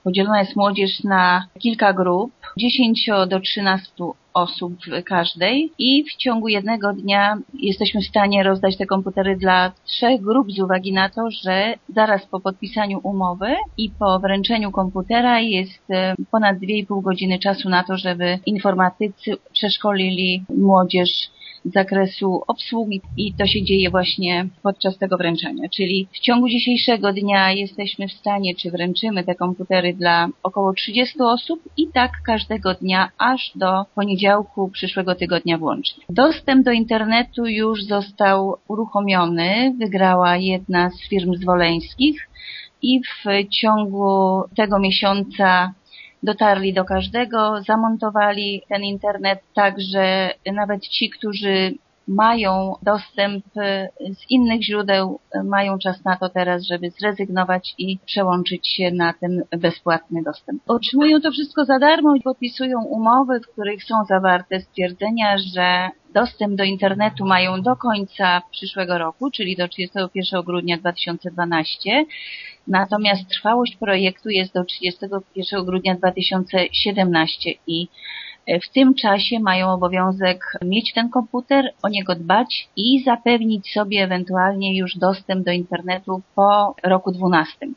„W ciągu tygodnia sprzęt odbierze w sumie 140 uczniów z całej gminy” – zapowiada burmistrz Zwolenia Bogusława Jaworska: